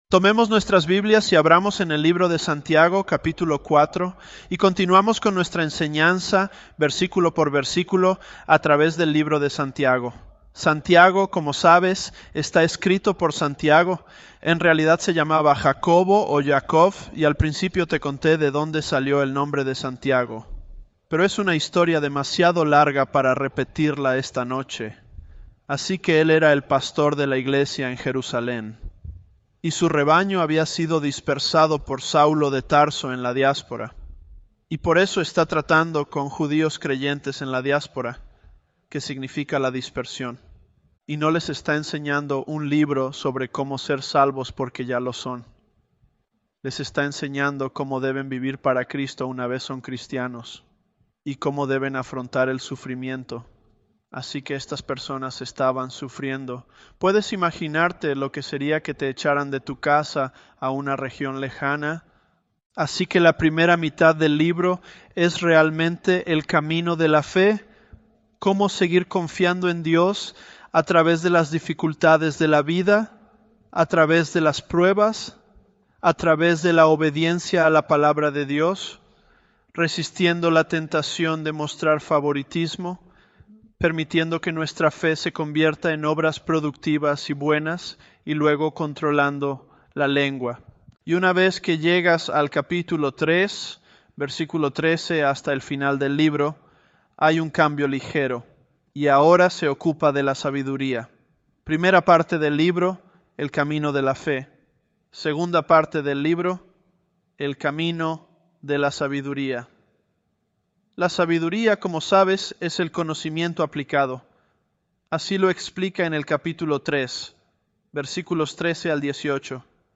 ElevenLabs_James023.mp3